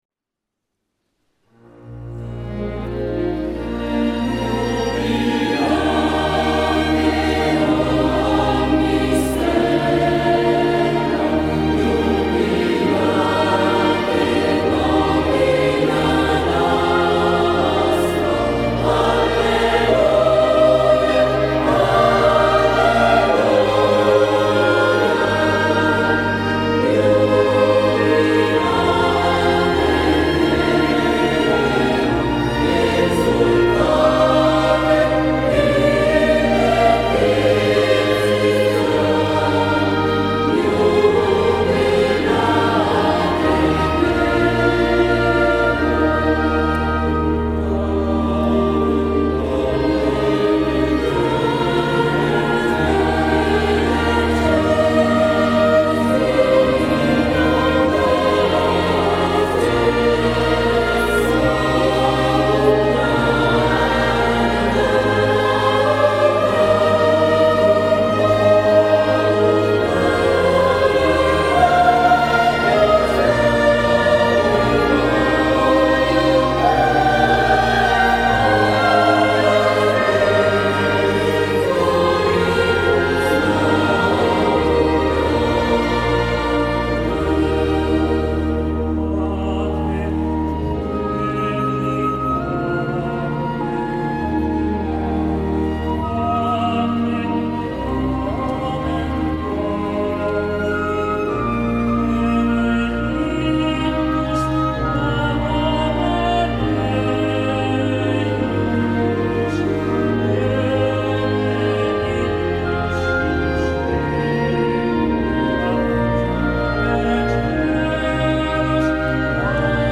Sabato 05 maggio 2012 la corale ha eseguito la prima edizione del Concerto di S. Eurosia, in collaborazione con l'orchestra "L'Incanto Armonico" di Pisogne (BS).